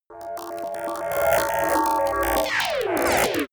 Royalty free sounds: Digital